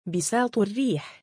♪ 音声サンプル：下の「文語的発音（休止法）」に相当する読み方です